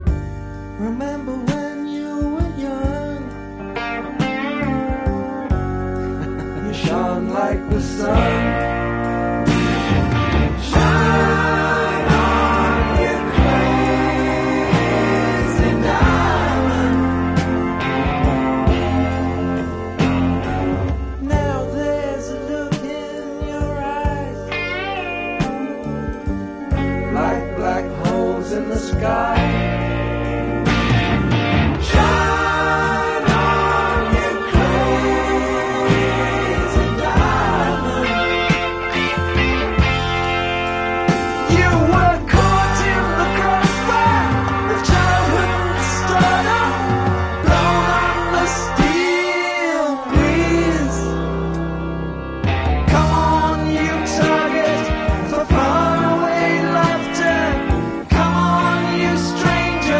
Let’s clean this WYWH Blue vinyl record!